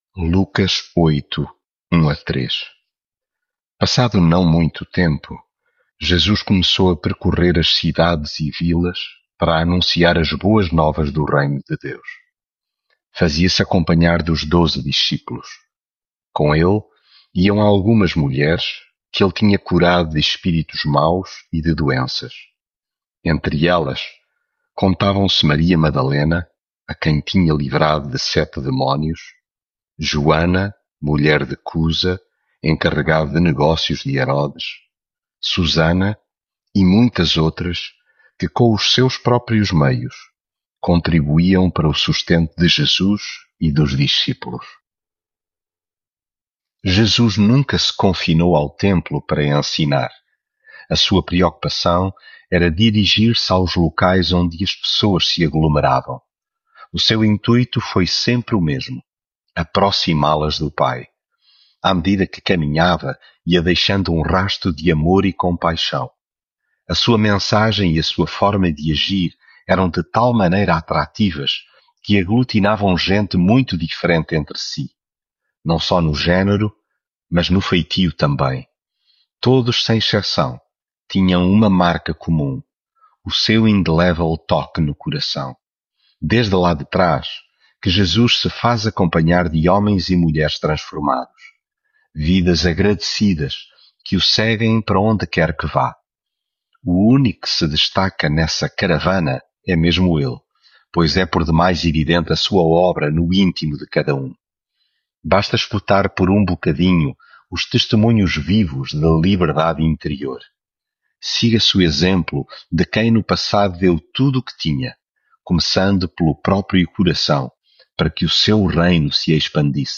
devocional Lucas leitura bíblica Passado não muito tempo, Jesus começou a percorrer as cidades e vilas para anunciar as boas novas do reino de Deus....